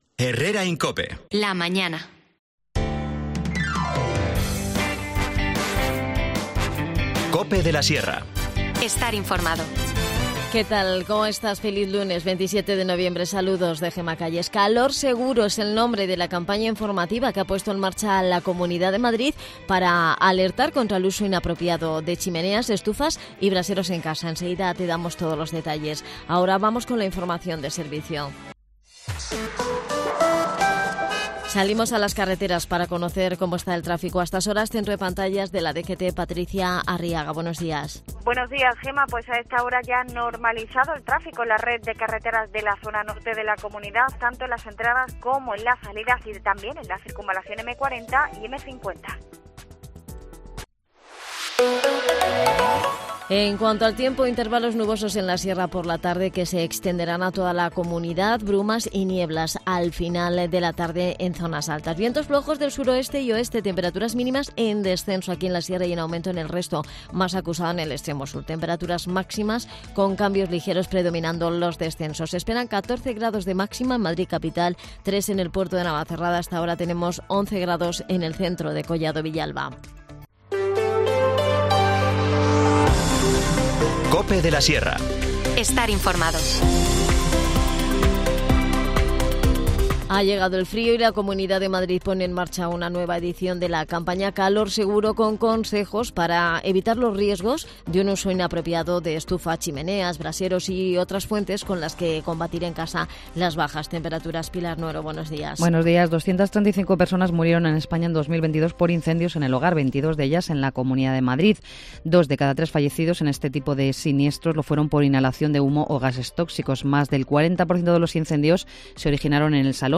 Nos cuenta todos los detalles Mayte Bertomeu, concejal de Comercio.
Las desconexiones locales son espacios de 10 minutos de duración que se emiten en COPE, de lunes a viernes.